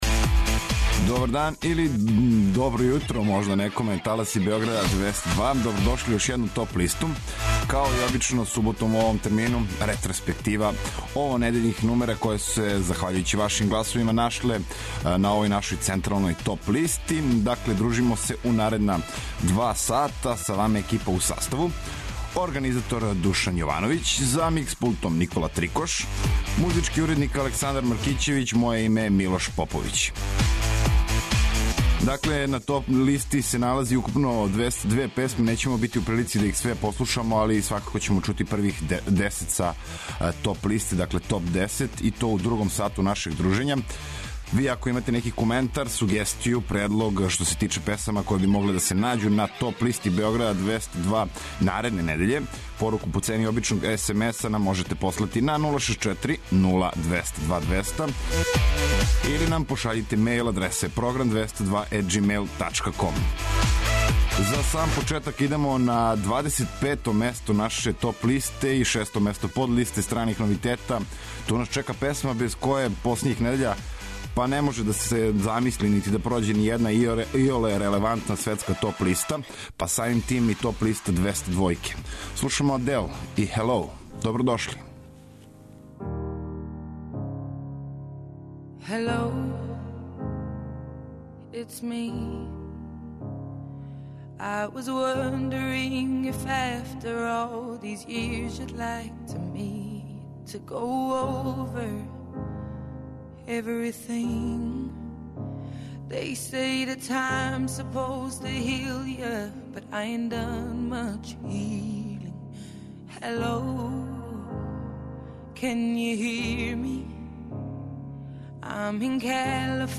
Топ листа Београда 202 и ове недеље обилује бројним домаћим и страним новитетима, као и добро познатим хитовима, који се налазе на различитим жанровским подлистама.